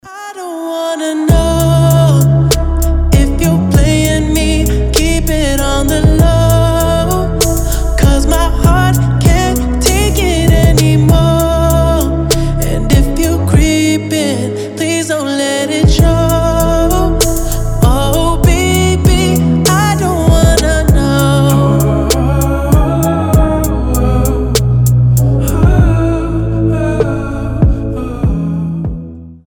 • Качество: 320, Stereo
красивый мужской голос
мелодичные
медленные
RnB